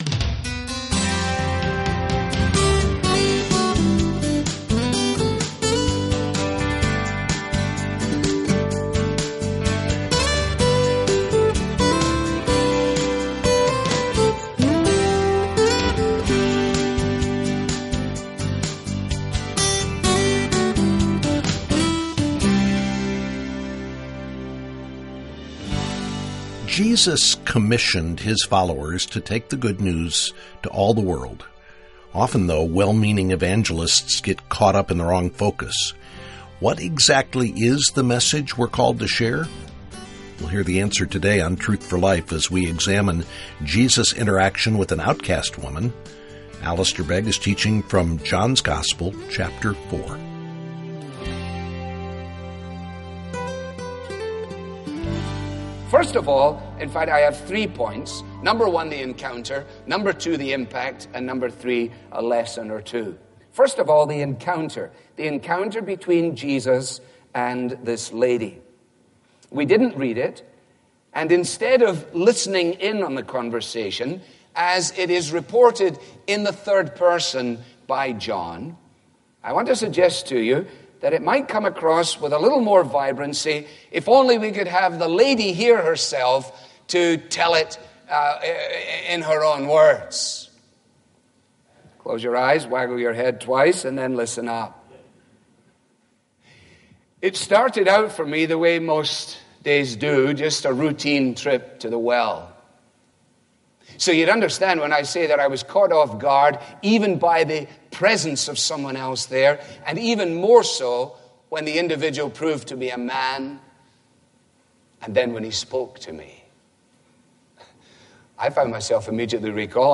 Daily teaching from Truth For Life relies on your prayer and financial support.